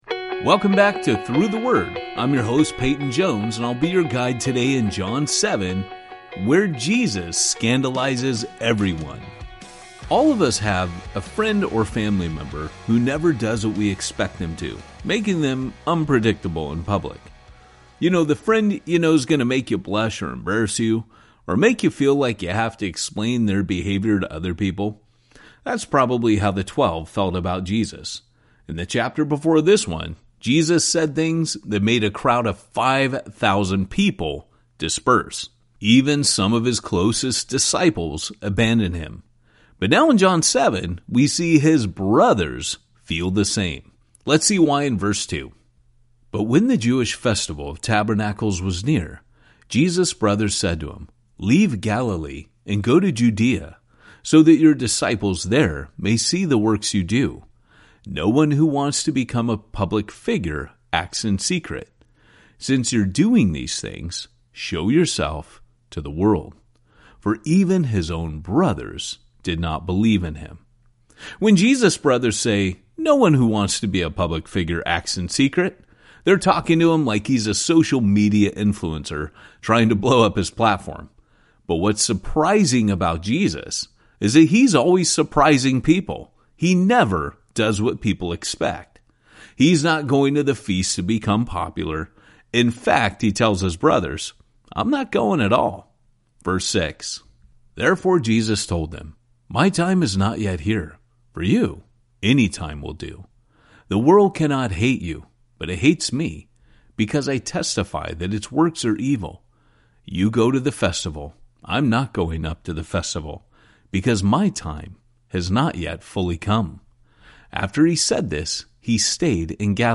The story comes alive each day as Through the Word’s ten-minute audio guides walk you through each chapter with clear explanation and engaging storytelling.